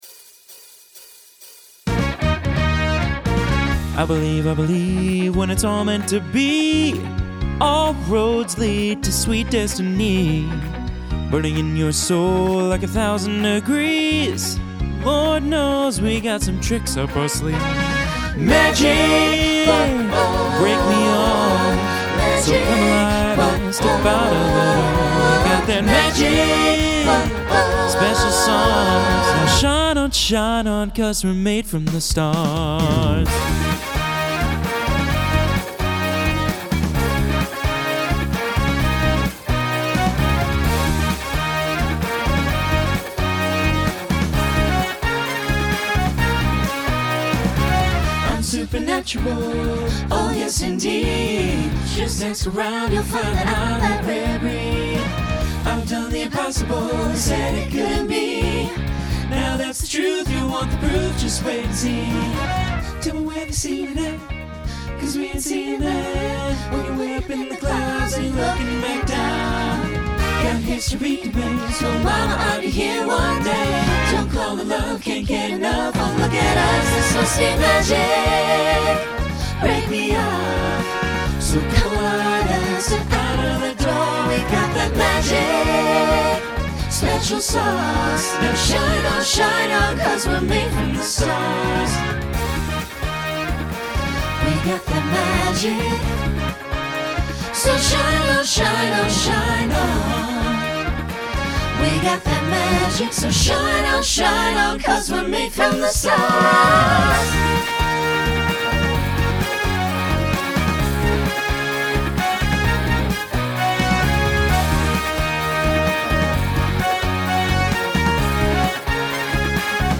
Genre Pop/Dance Instrumental combo
Opener Voicing SATB